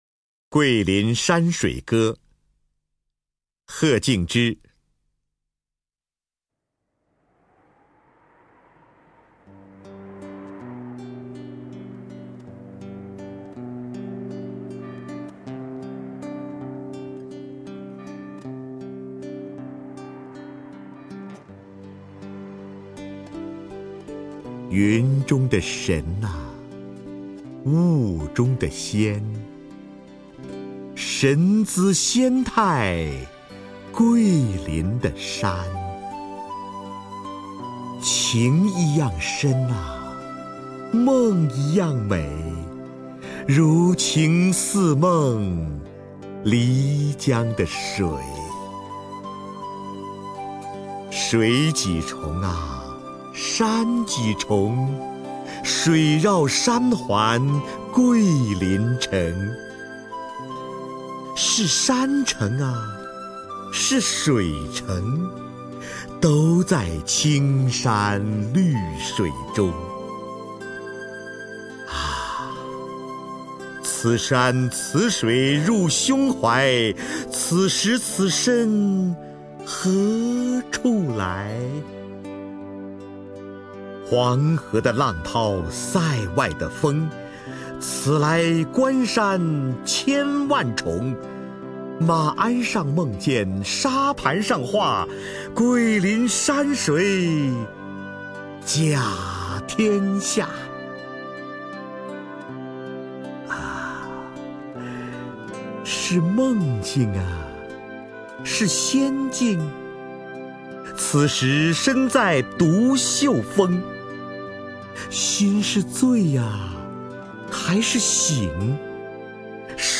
首页 视听 名家朗诵欣赏 瞿弦和
瞿弦和朗诵：《桂林山水歌》(贺敬之)